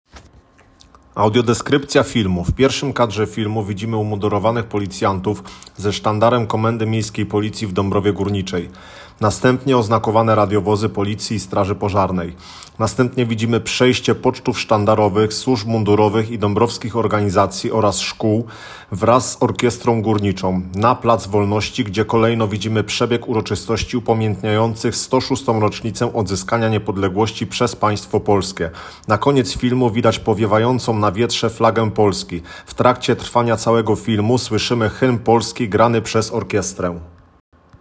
Nagranie audio Audiodeskrypcja_filmu_Swieto_Niepodleglosci_w_Dabrowie_Gorniczej.m4a